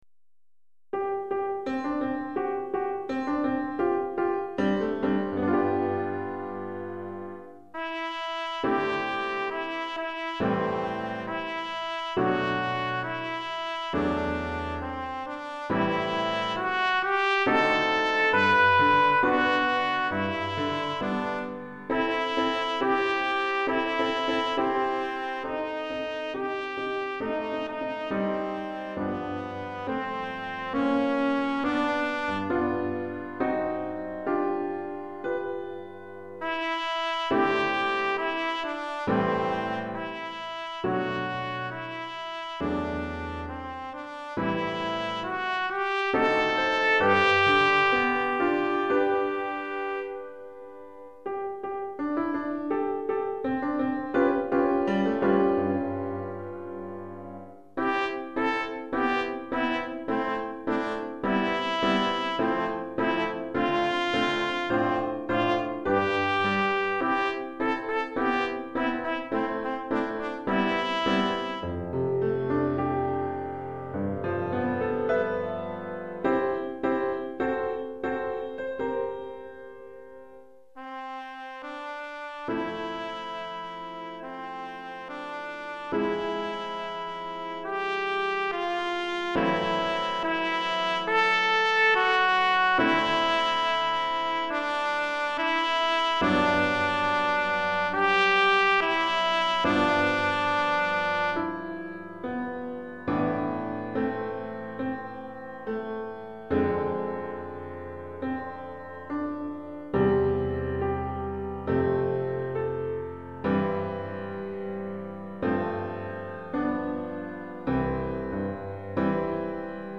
Pour trompette (ou cornet) et piano DEGRE CYCLE 1